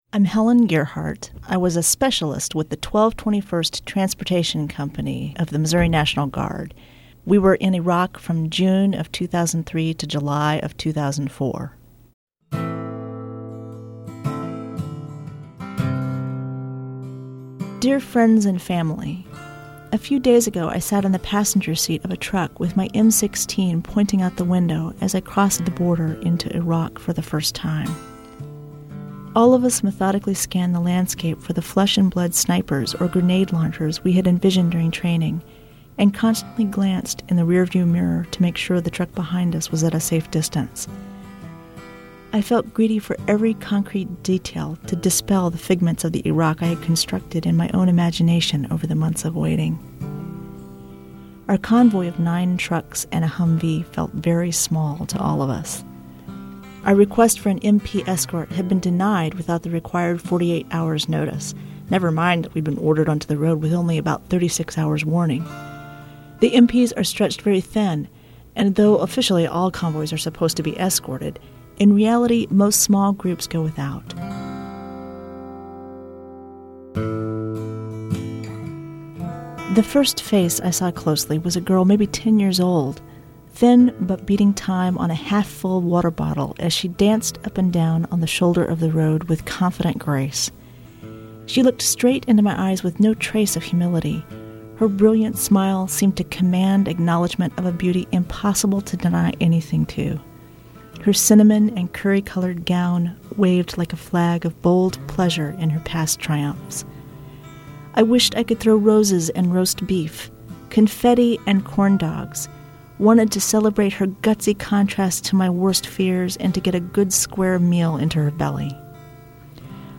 Here Among These Ruins"- 3:46 MP3 Perspective on her emailed-essay published in Operation Homecoming.